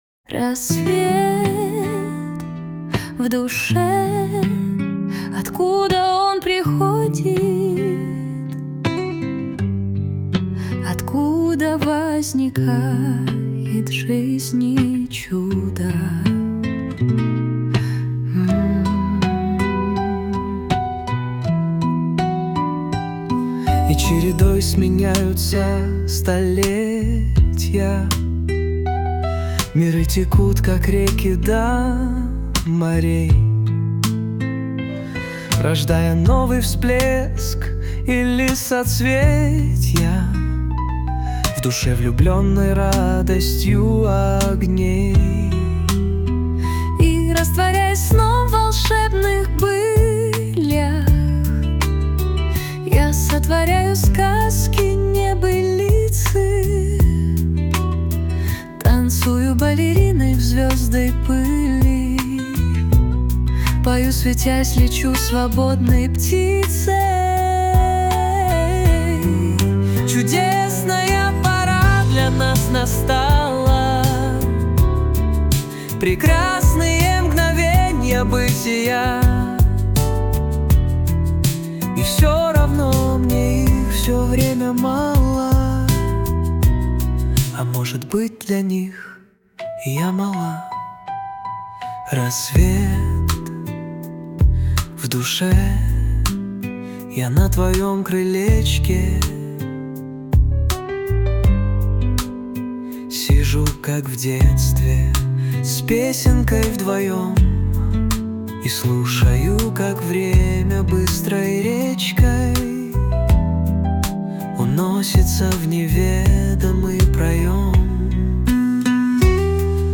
Откуда возникает жизни чудо - муз. композиция